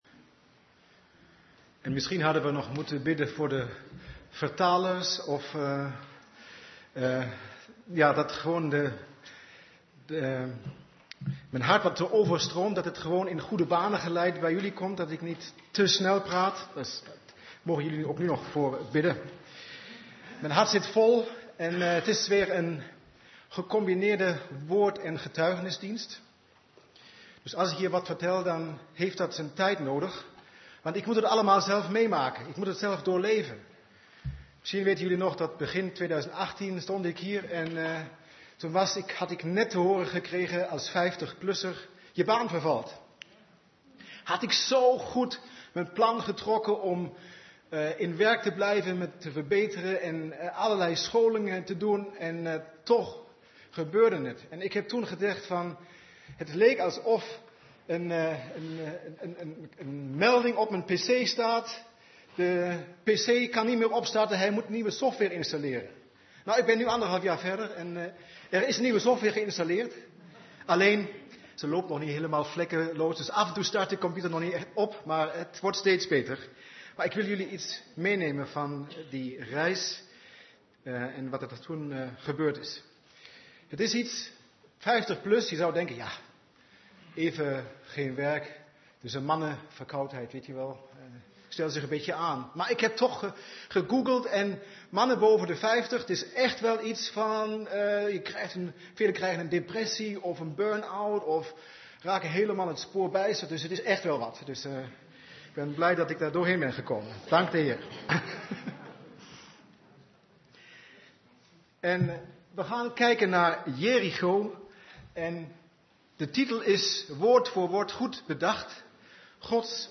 Toespraak van 22 september: Jericho- Gods perfecte plan voor geestelijke overwinning - De Bron Eindhoven